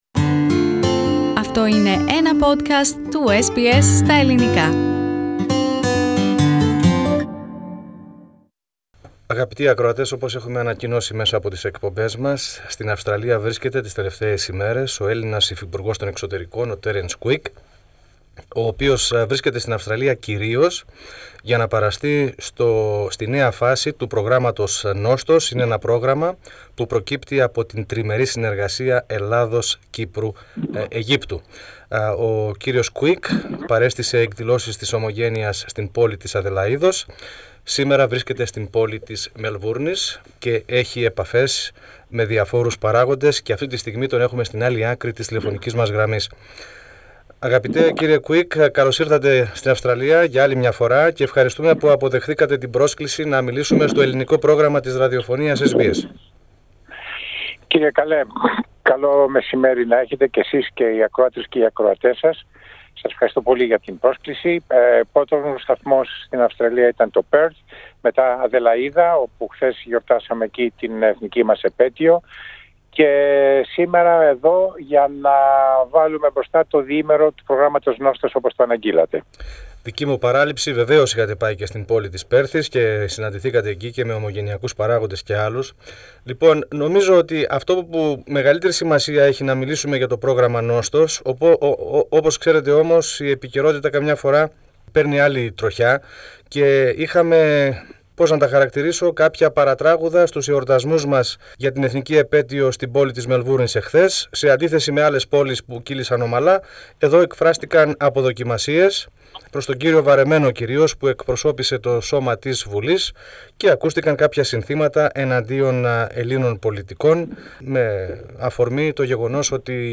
O υφυπουργός Εξωτερικών για θέματα του Απόδημου Ελληνισμού, Τέρενς Κουίκ, μετά τις επισκέψεις του στην Πέρθη και στην Αδελαΐδα για την Εθνική Eπέτειο της 25ης Μαρτίου, βρίσκεται στη Μελβούρνη για τις εργασίες του Προγράμματος "Νόστος ΙΙΙ" και μίλησε στο Ελληνικό Πρόγραμμα της Ραδιοφωνίας SBS.